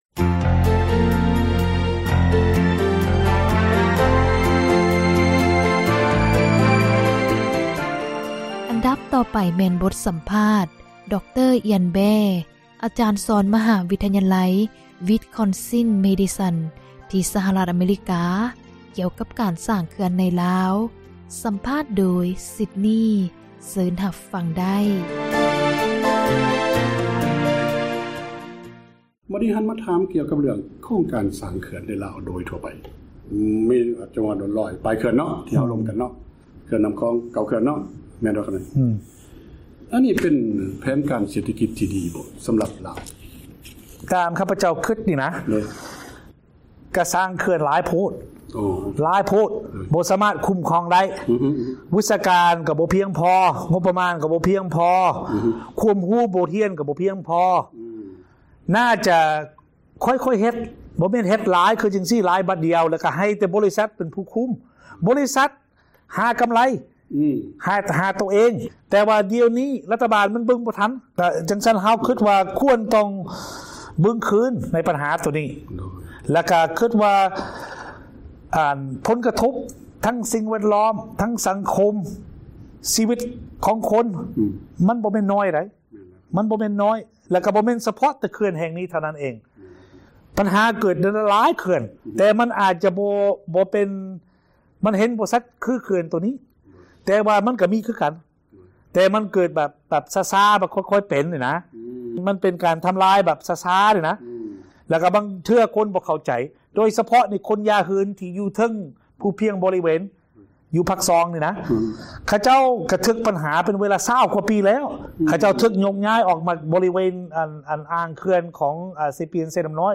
ບົດສໍາພາດ